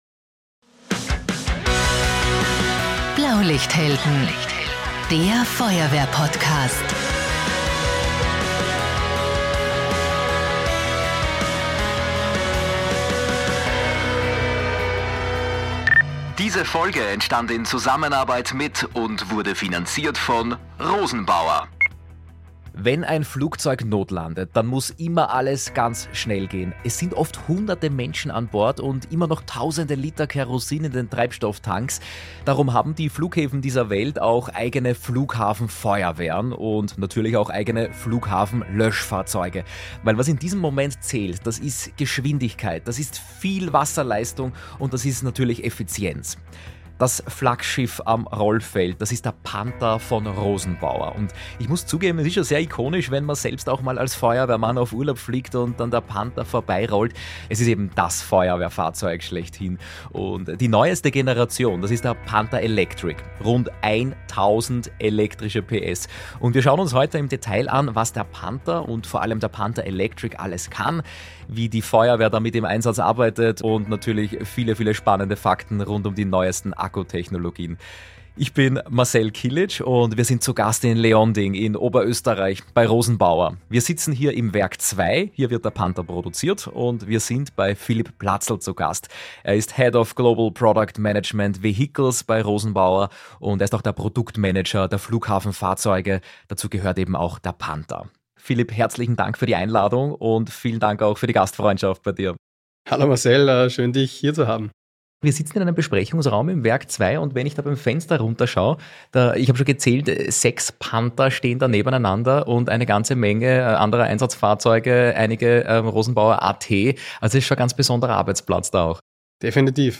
In dieser Folge melden wir uns „live“ von der großen Award-Show im Ö3-Haus am Wiener Küniglberg.